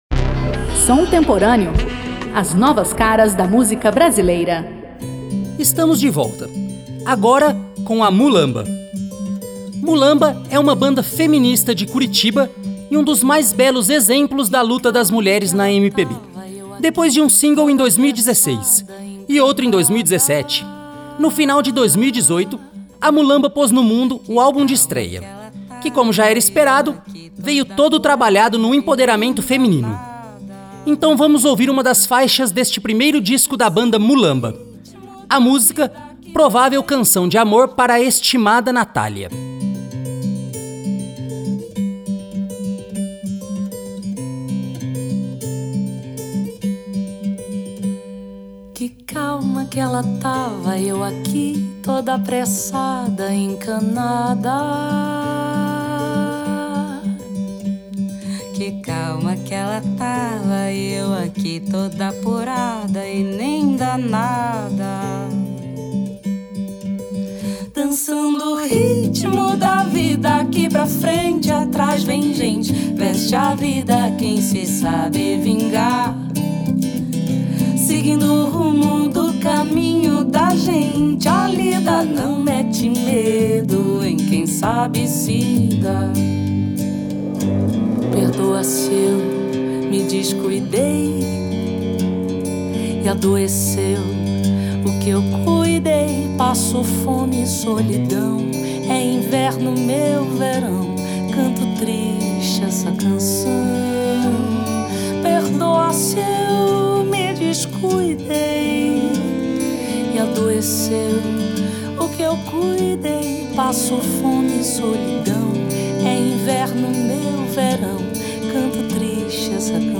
Música Brasileira música contemporânea